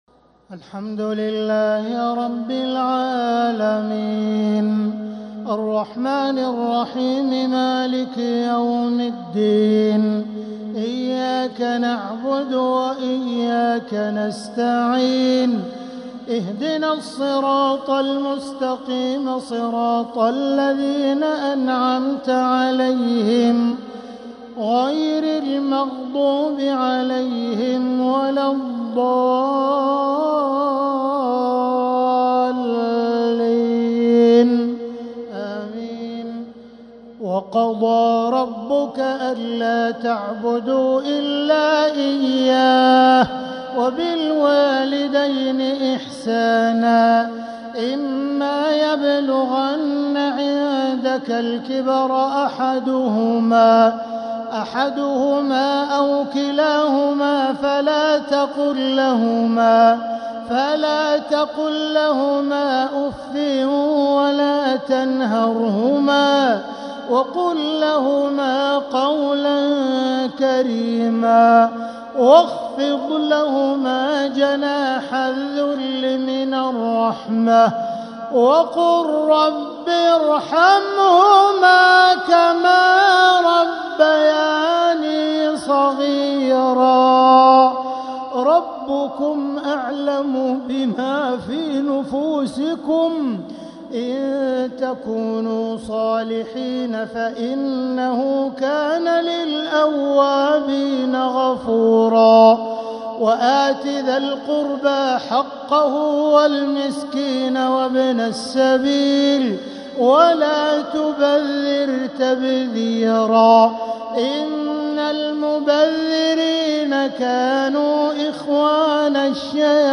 تراويح ليلة 19 رمضان 1446هـ من سورة الإسراء (23-52) | taraweeh 19th niqht Ramadan1446H Surah Al-Israa > تراويح الحرم المكي عام 1446 🕋 > التراويح - تلاوات الحرمين